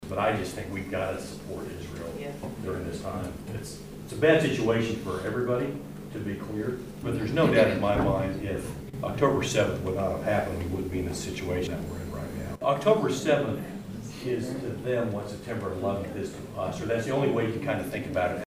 United States Congressman Tracey Mann visited Riley County on Thursday for a Town Hall meeting at the Leonardville Community Center.